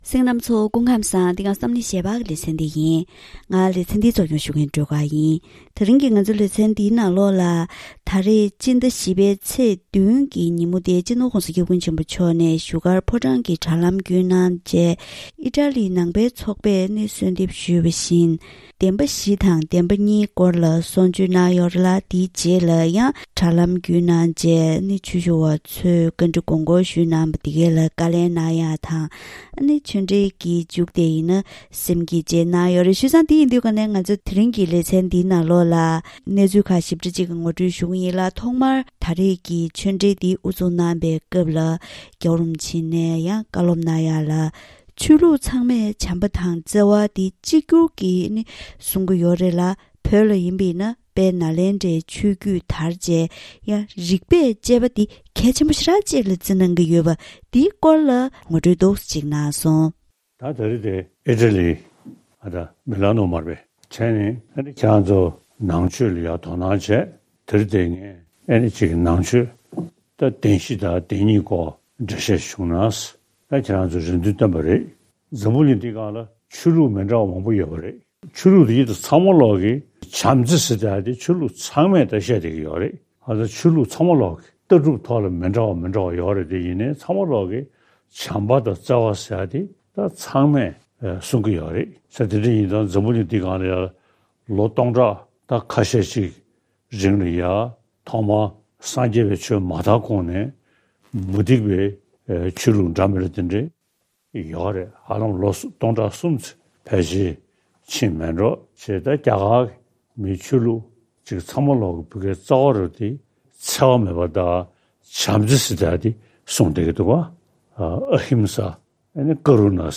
གཏམ་གླེང་ཞལ་པར་ལེ་ཚན